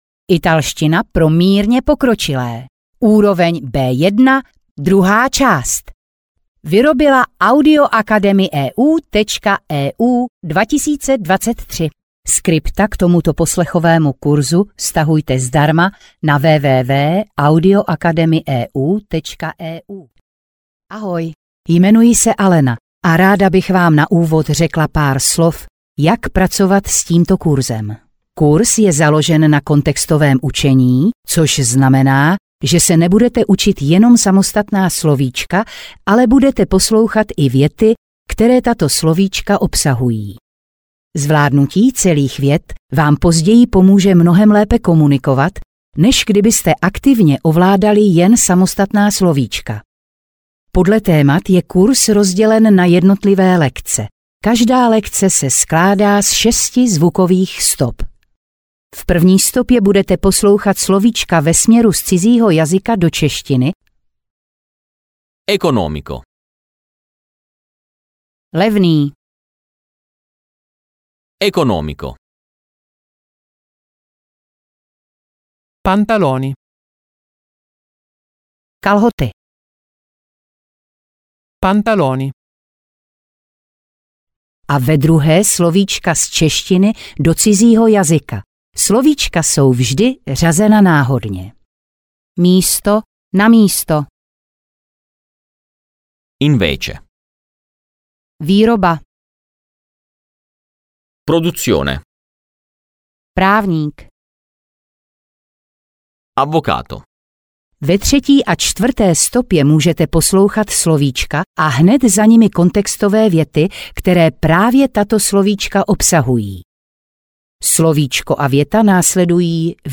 Vyberte Audiokniha 329 Kč Další informace
Dále máte k dispozici slovíčko následované příkladovou větou, opět v obou variantách překladu (stopa 3 a 4).